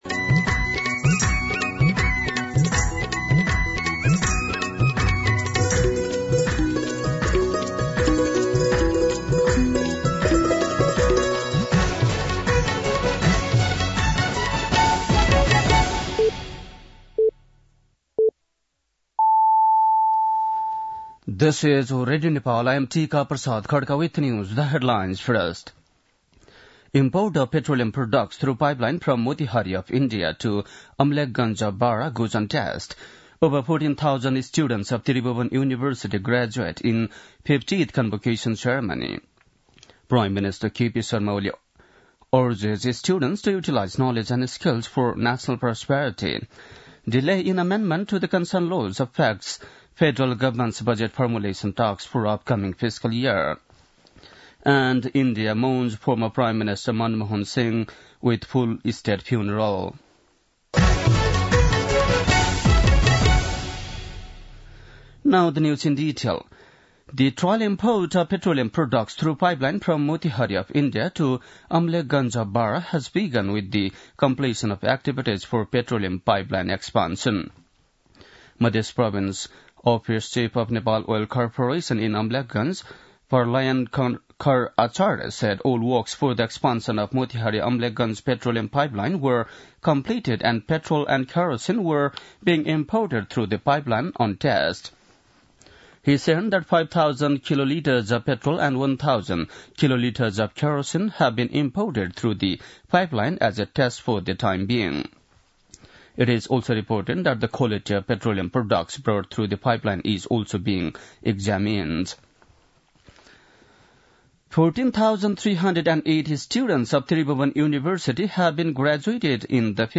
बेलुकी ८ बजेको अङ्ग्रेजी समाचार : १४ पुष , २०८१
8-pm-news-9-13.mp3